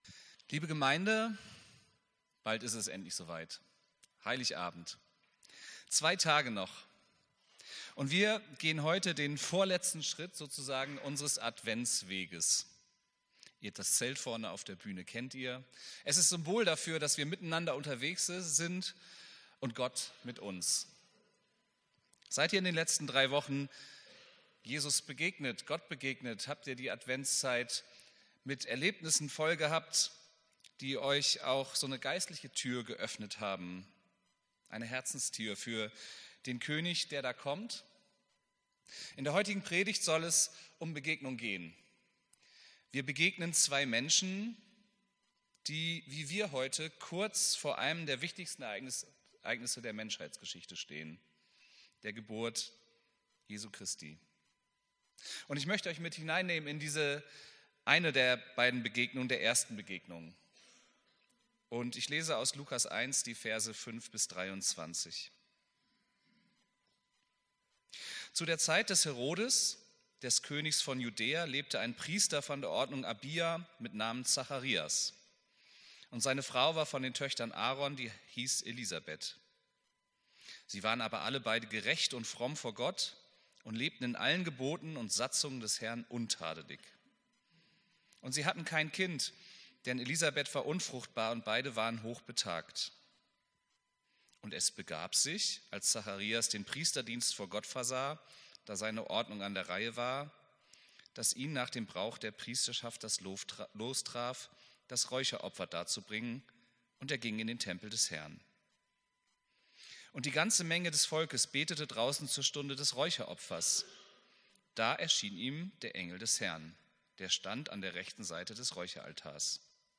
Predigt vom 22.12.2024